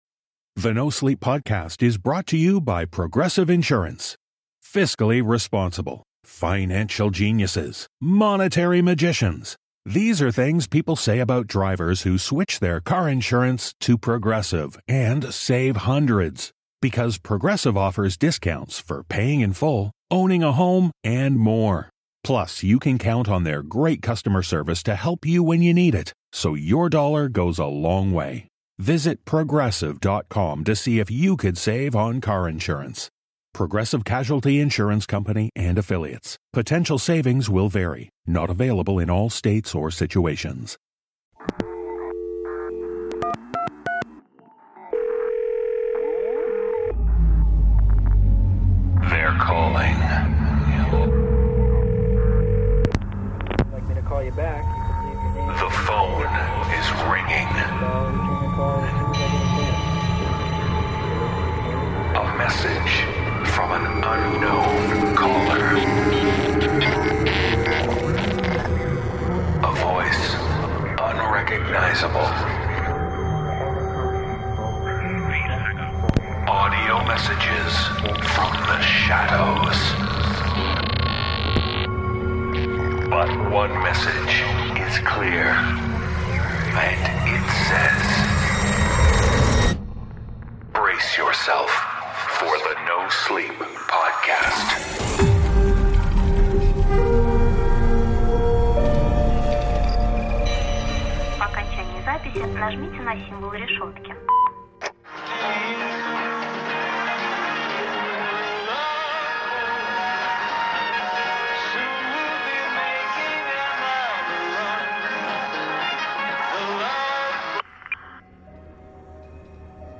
The voices are calling with tales of affection affliction.